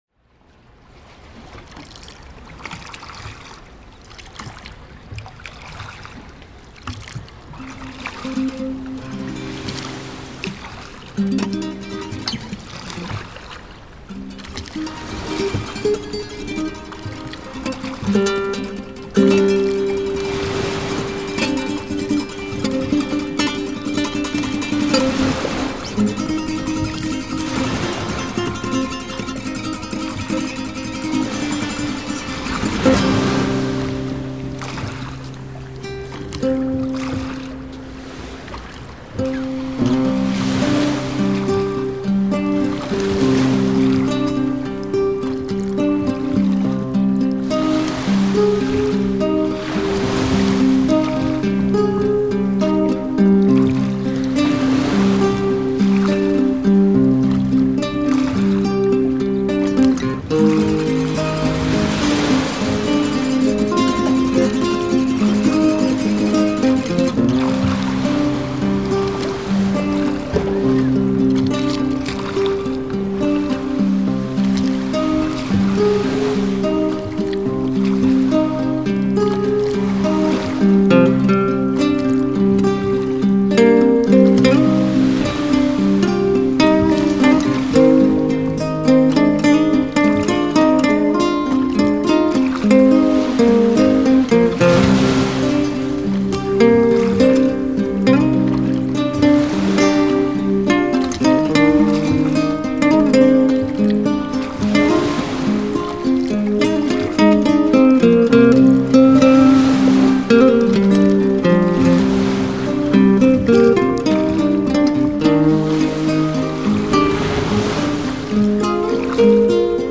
风格: Relax / Chillout
呢喃女声和海鸟呼唤穿插，梦幻电音与奇异节拍铺陈，实乃景不醉人人自醉！